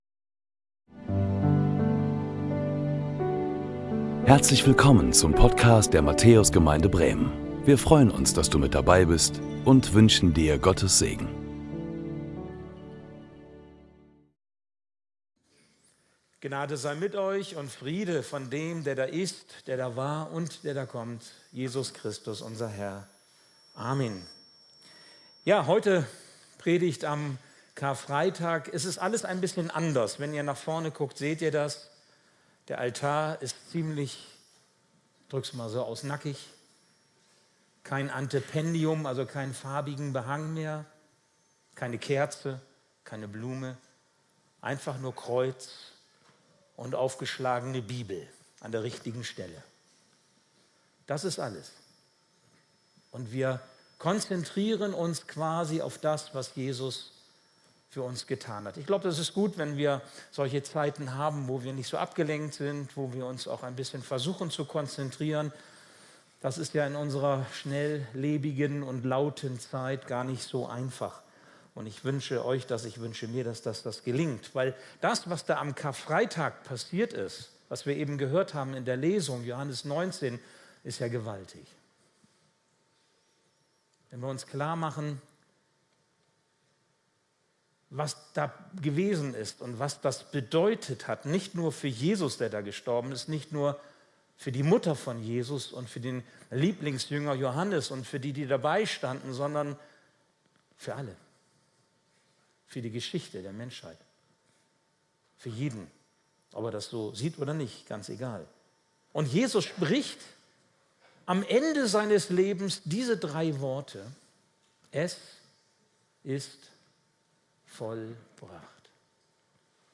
Predigten der Matthäus Gemeinde Bremen Karfreitag "Vollbracht!"